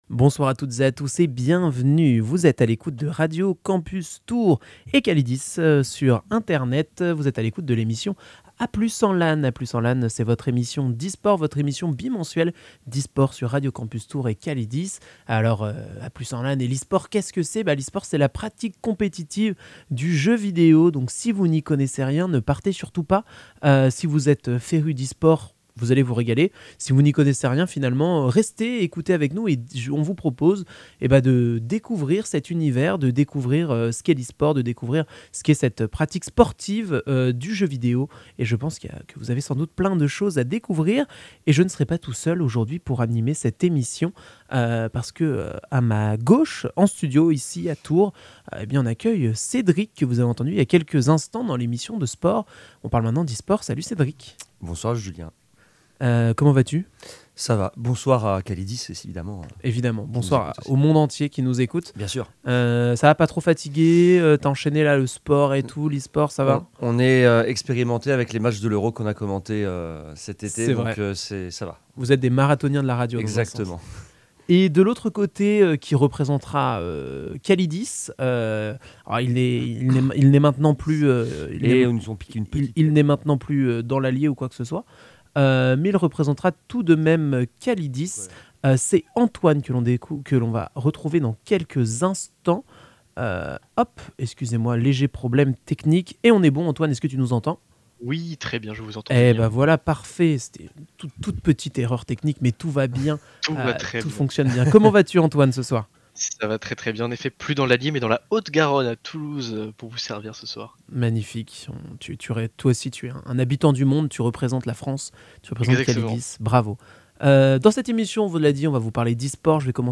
Cette année, vous retrouverez votre émission d’esport en direct une semaine sur 2, sur Calidis mais aussi sur Radio Campus Tours !